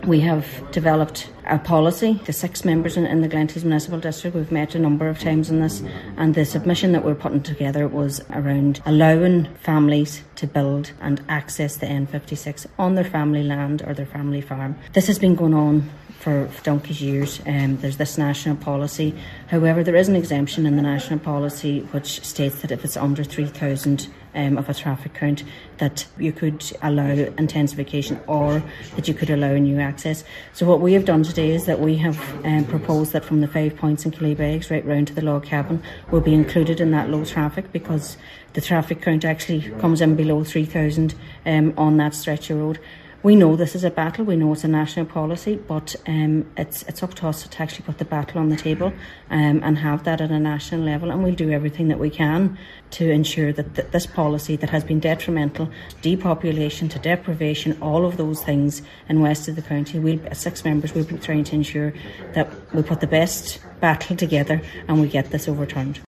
Councillor Marie Therese Gallagher says a policy change is needed to enable the continued survival of rural and Gaeltacht communities: